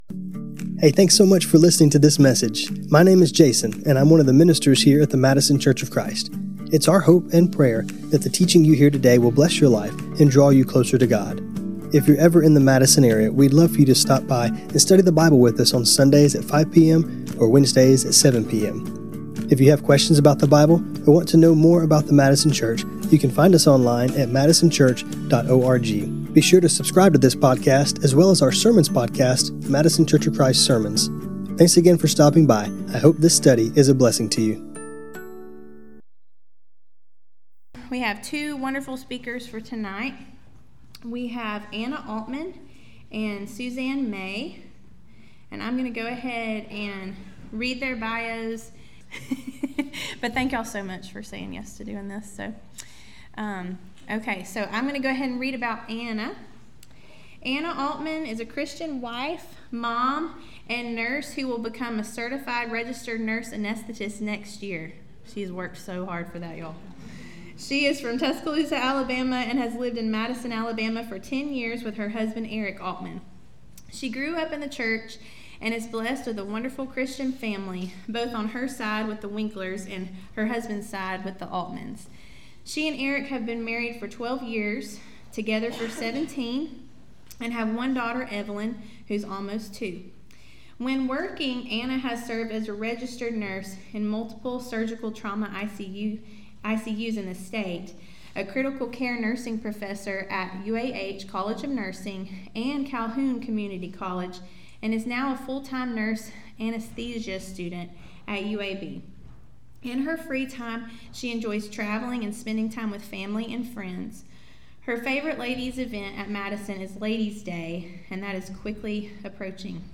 When we go through difficult times and even wonderful times, God has given us something that is constant, His word. In this class, we will hear from some of our own sisters as they share the scriptures that have carried them through different life circumstances both good and bad.